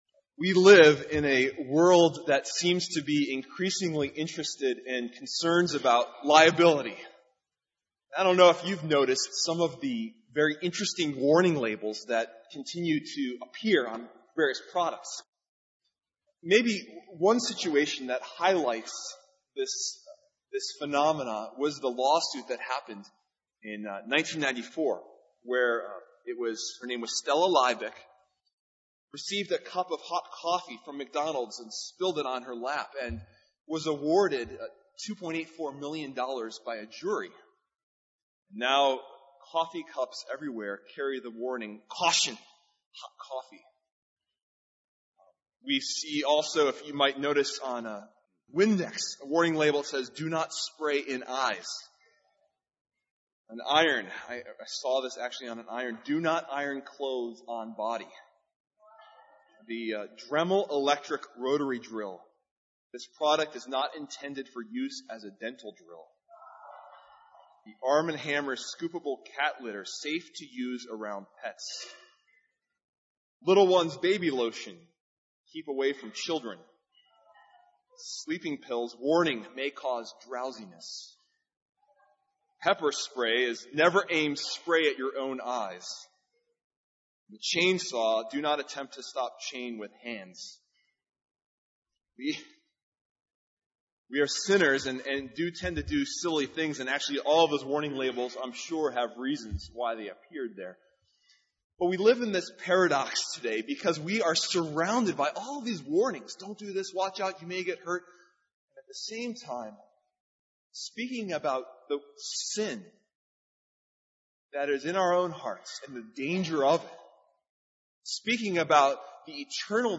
Guest Preacher Passage: Ezekiel 3:16-27, 1 Thessalonians 5:1-15 Service Type: Sunday Morning « Week 3